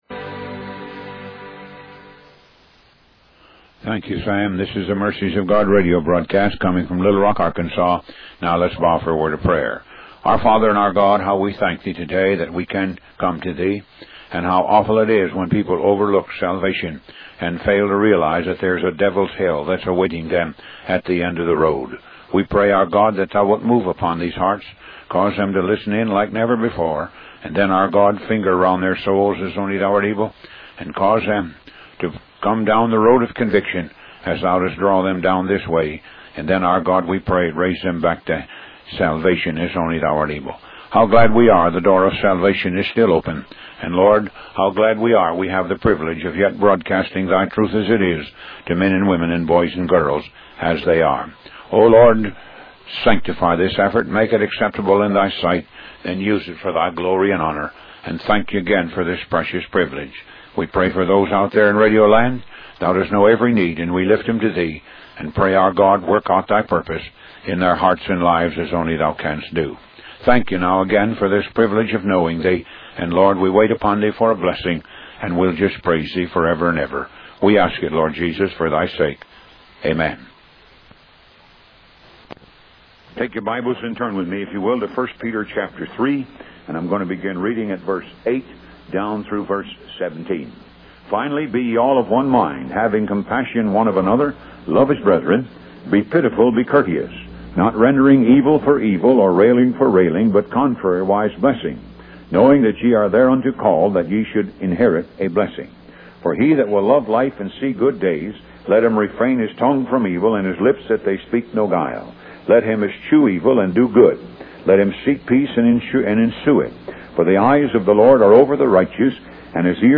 Talk Show Episode, Audio Podcast, Moga - Mercies Of God Association and The Eyes Of Loyalty on , show guests , about The Eyes Of Loyalty, categorized as Health & Lifestyle,History,Love & Relationships,Philosophy,Psychology,Christianity,Inspirational,Motivational,Society and Culture